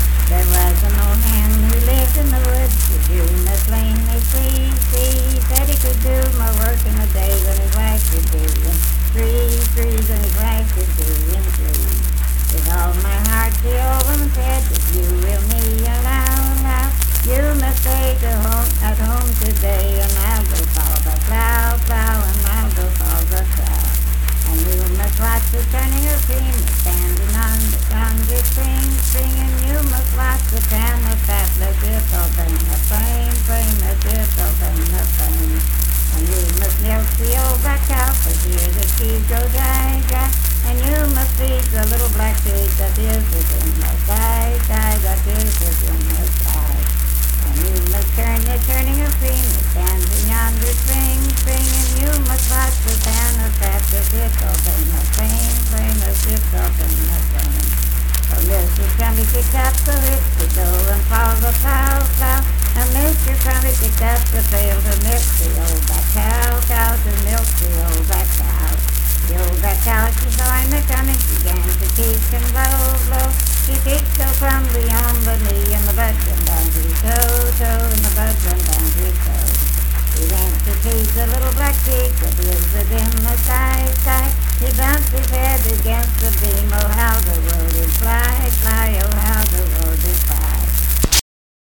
Unaccompanied vocal music performance
Verse-refrain 10 (5w/R).
Voice (sung)